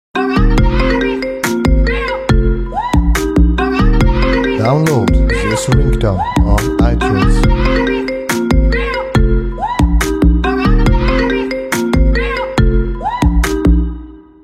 Categoria Marimba Remix